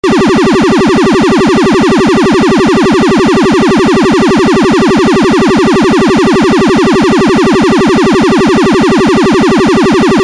Federal Signal Siren Tone UNITROL ALARM